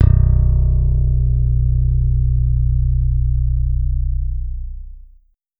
KAGI FRET -R.wav